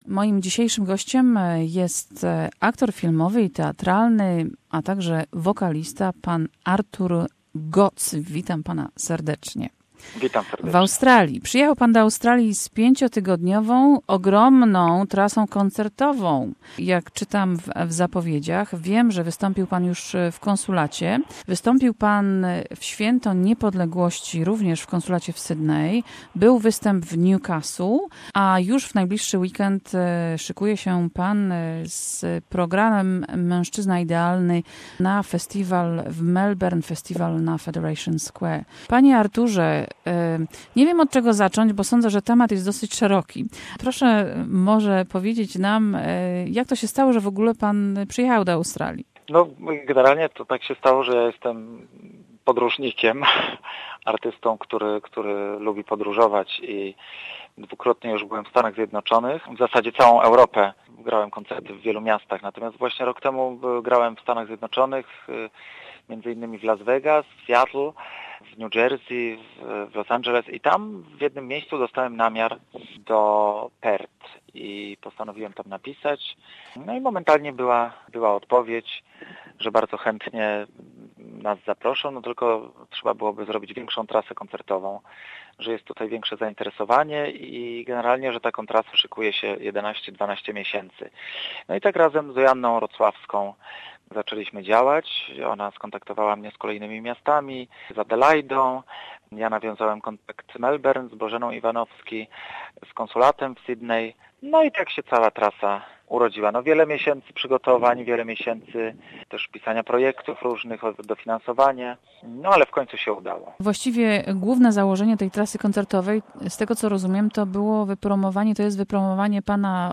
Rozmowa z polskim aktorem I wokalista, ktory wystapi w Niedziele 13go Listopada na Festiwalu Federation Square, ze sobą do Australii przywiózł swoją najnowszą płyte "Mężczyzna prawie idealny"